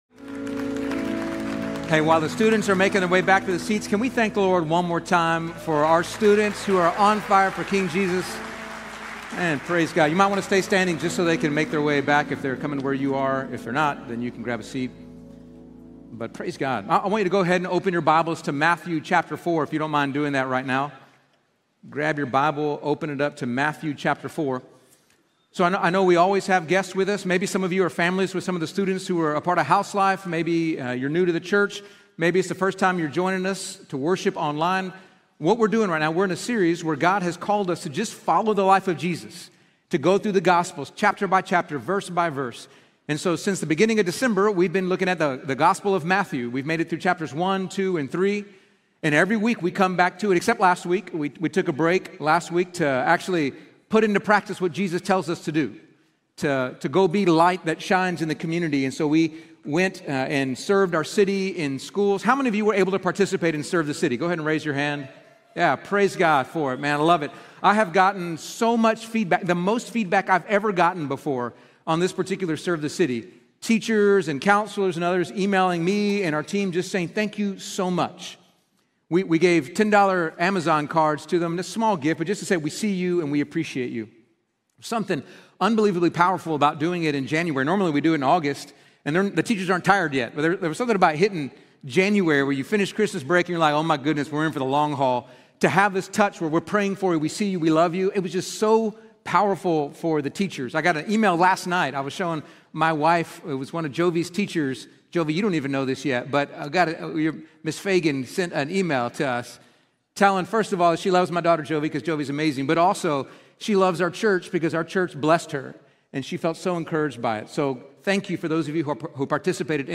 Quickly and easily listen to Fielder Church Sermons for free!